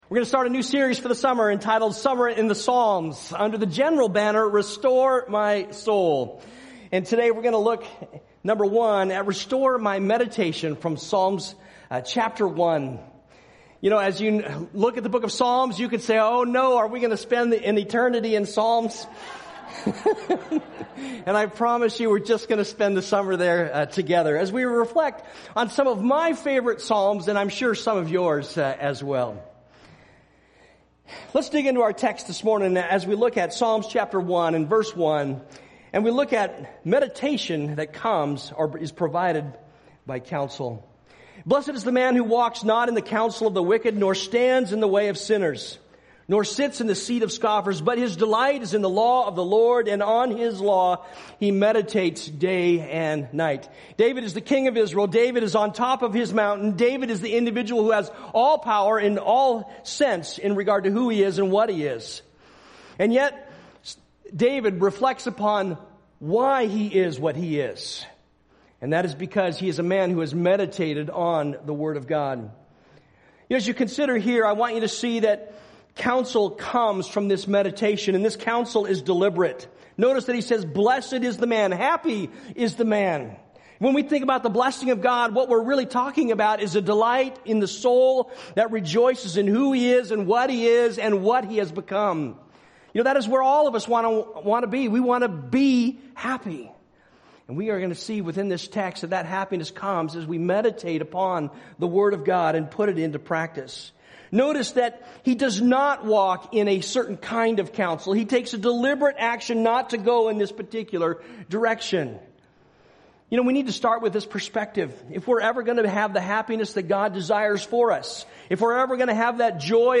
A message from the series "Summer in the Psalms."